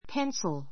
pénsl ペ ン ス る